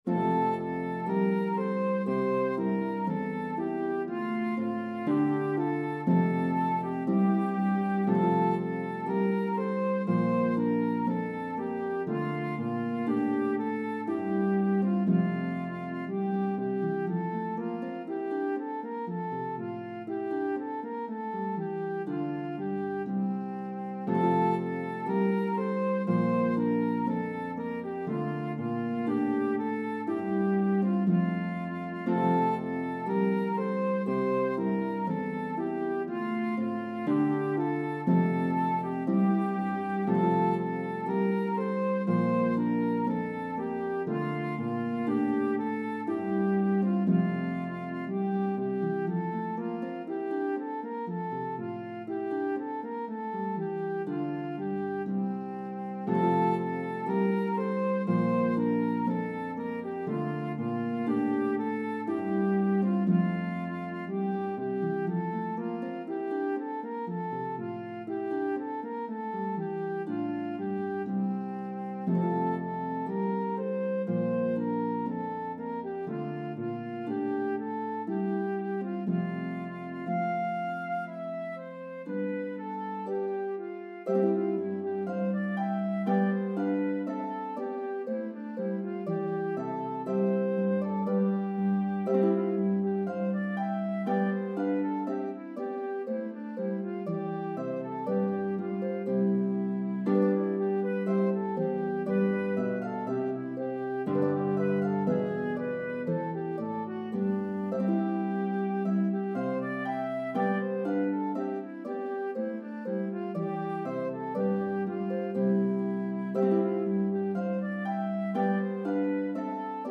Harp and Flute version